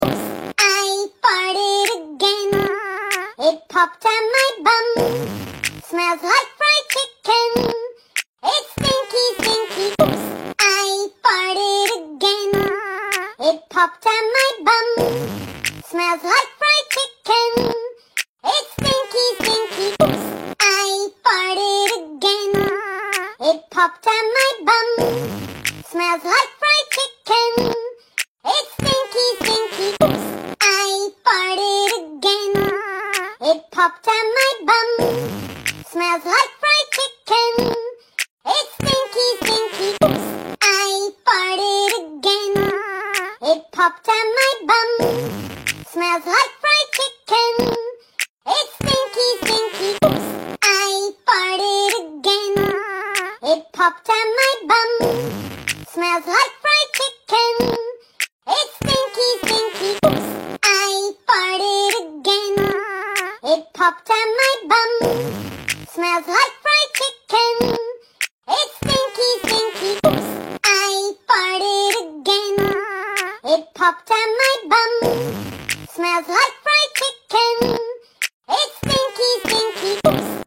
Meme Sound Effects
u3-Oopps-I-Farted-Again.mp3